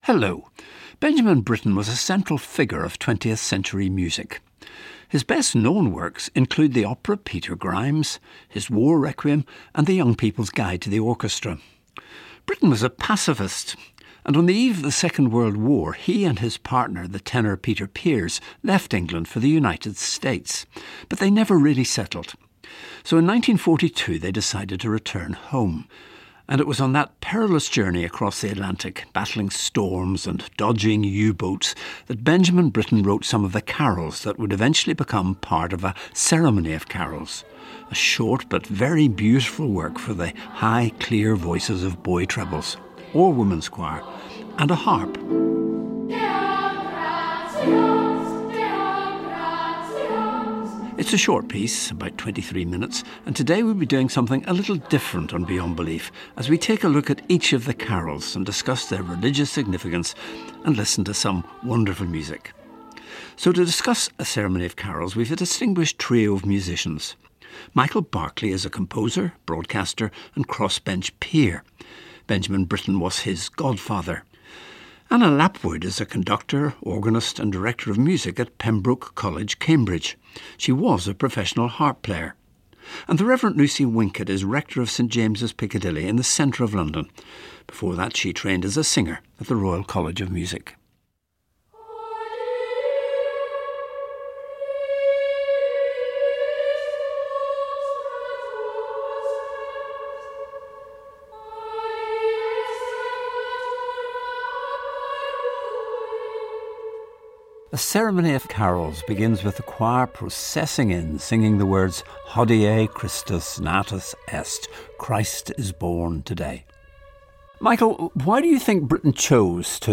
Listen to Beyond Belief on BBC Radio 4 which was broadcast on Monday 20 December 2021.